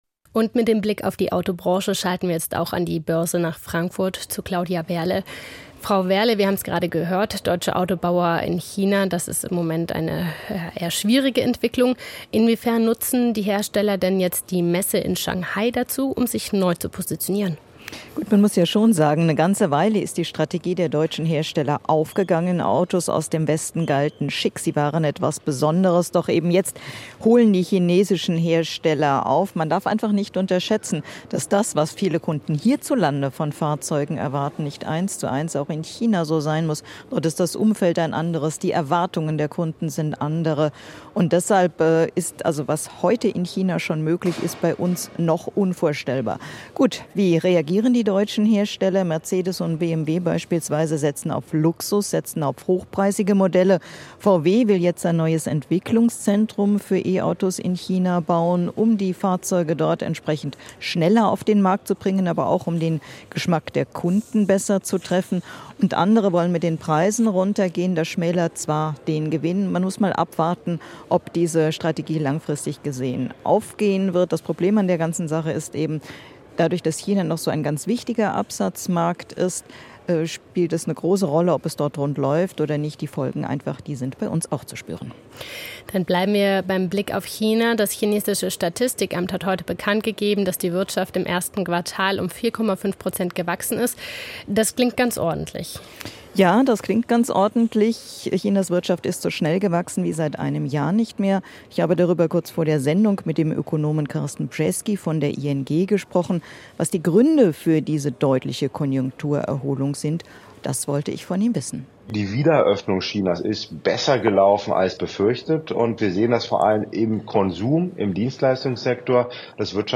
Börsengespräch aus Frankfurt